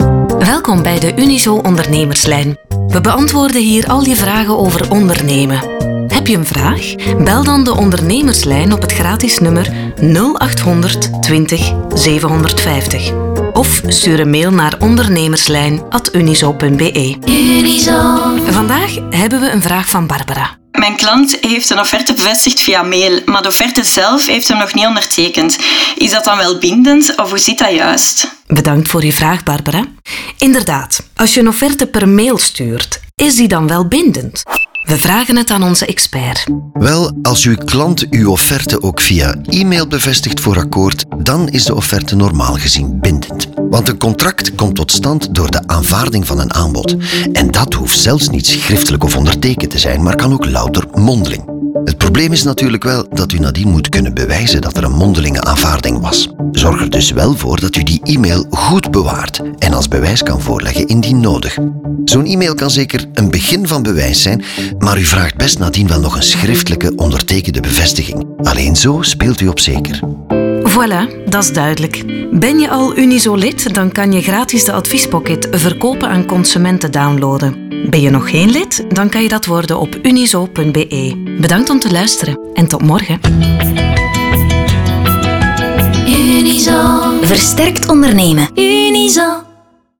Commercial, Natural, Playful, Reliable, Warm
Audio guide